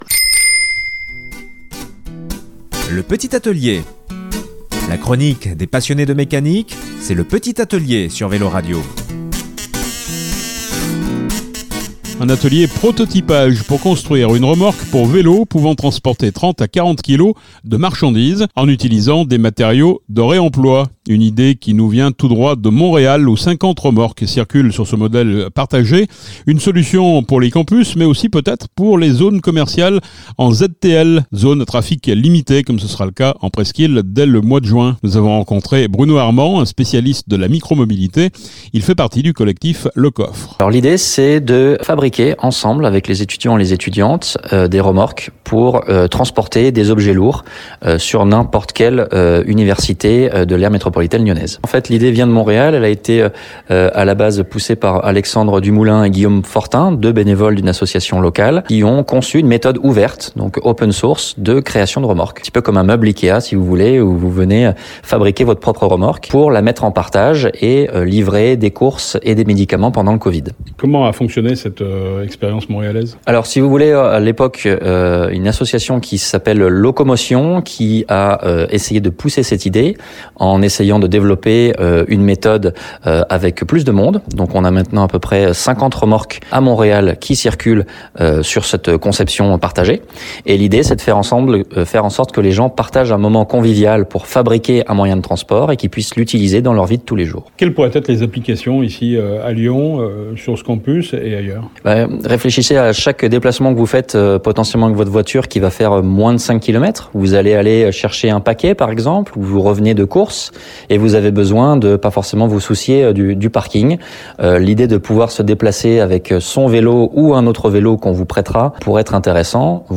Un atelier un peu parliculier en plein coeur du campus universitaire de la Doua…Il s’agit d’un atelier prototypage pour construire une remorque à vélo, pouvant transporter 30 à 40 kg de marchandises, en utilisant des matériaux de réemploi..